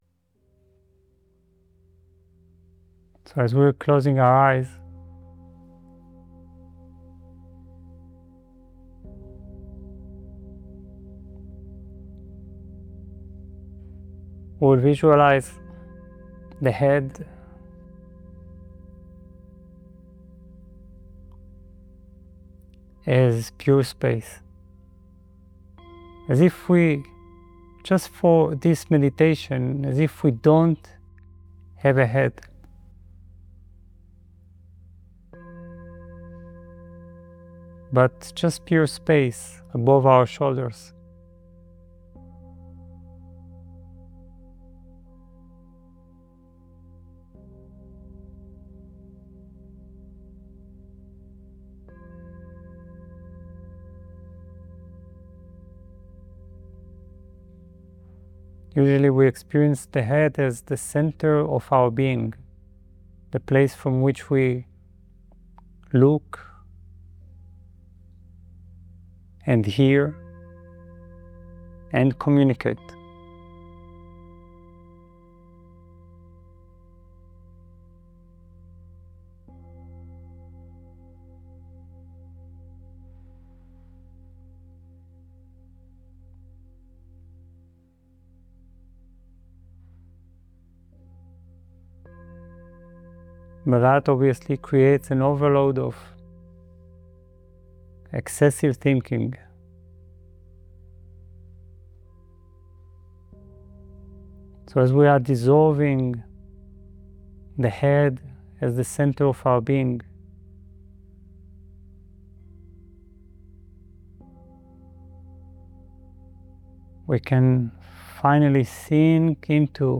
Inner Cave: Meditation (english)
EN-IFA-Retreat-InnerCave.mp3